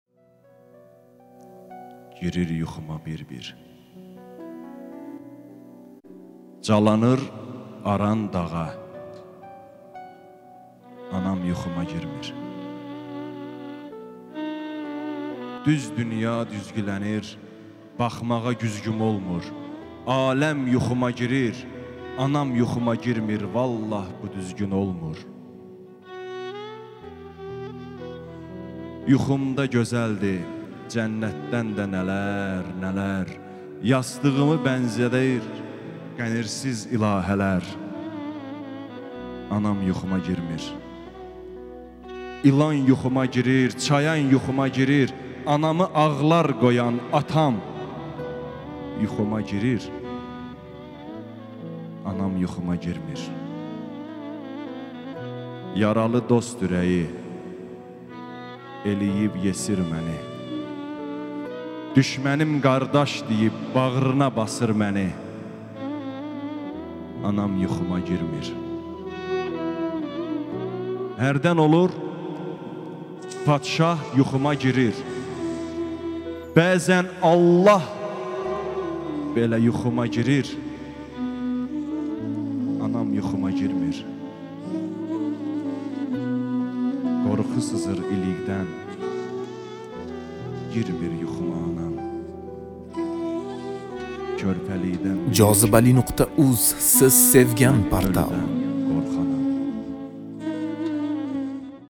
ŞEİR